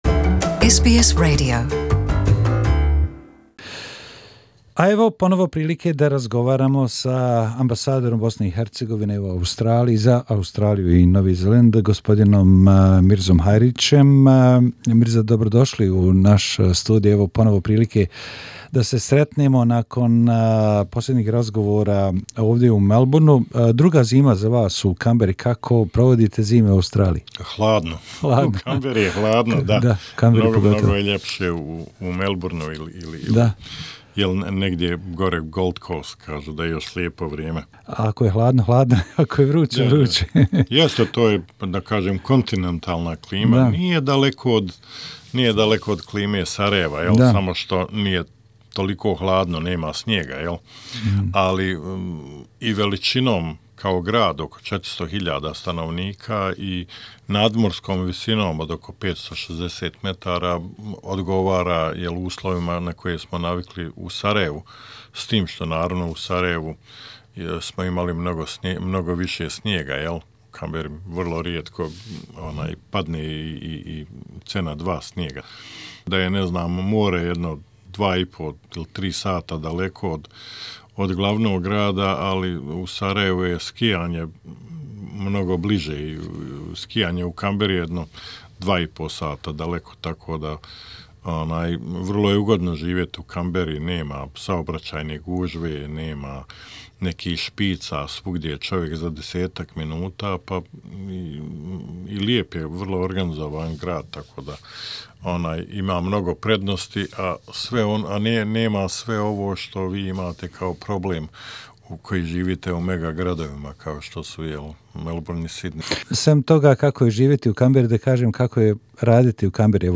HE Mr Mirza Hajric, Ambassador of Bosnia and Herzegovina in SBS studio Source